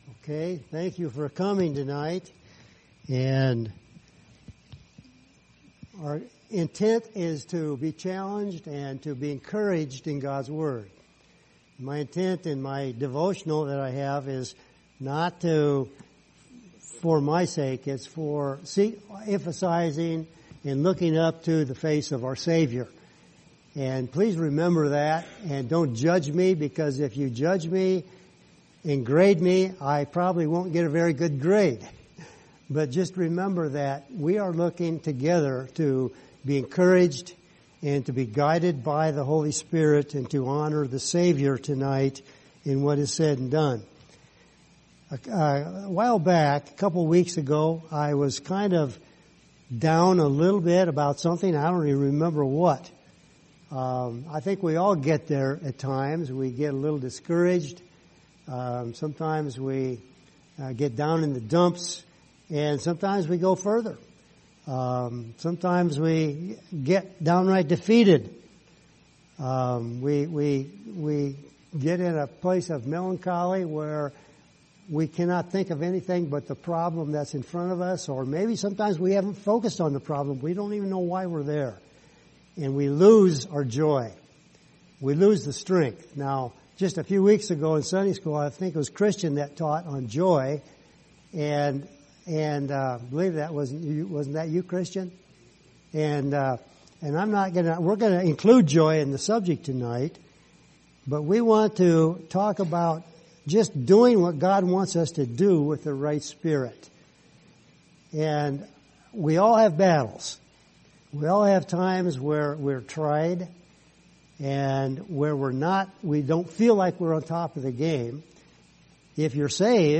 Passage: Psalm 40:8 Service Type: Wednesday Evening